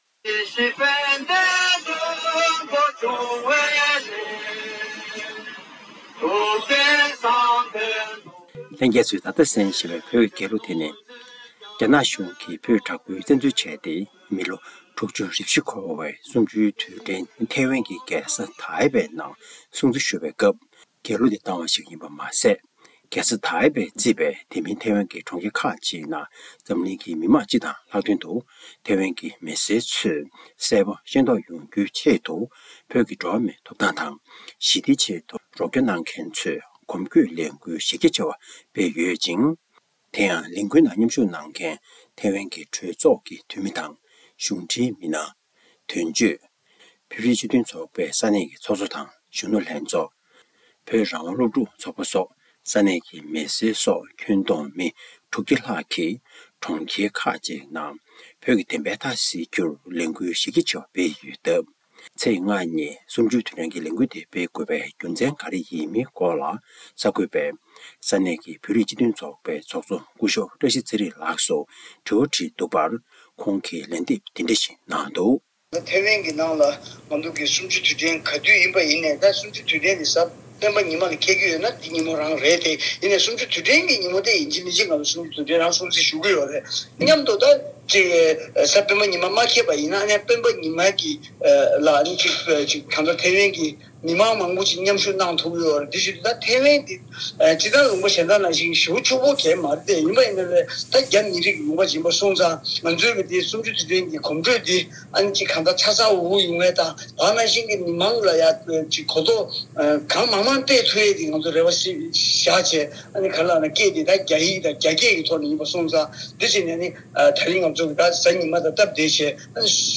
གནས་ཚུལ་ཕྱོགས་བསྒྲིགས་དང་སྙན་སྒྲོན་ཞུས་གནང་བ་འདིར་འཁོད་ཡོད།